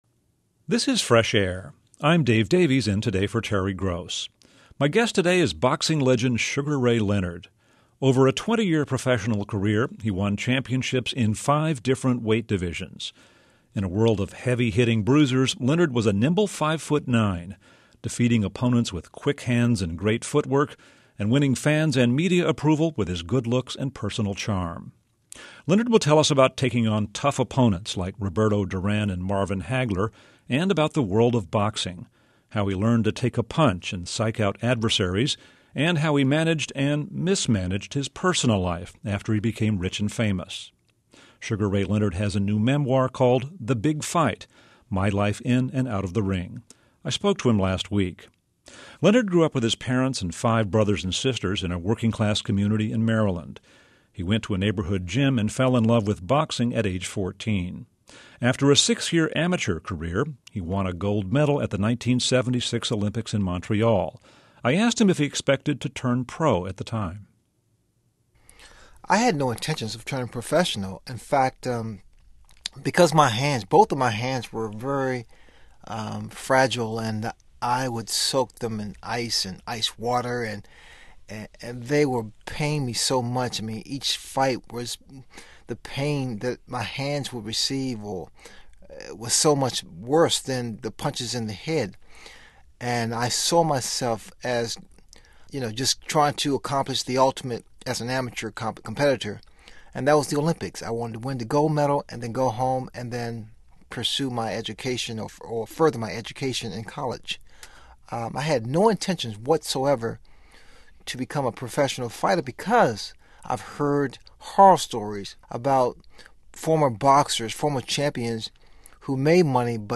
Boxing legend Sugar Ray Leonard conducted an extensive, 34 minute interview with NPR on his boxing career and other revelations made in his new autobiography The Big Fight.
npr_leonard_interview.mp3